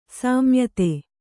♪ sāmyate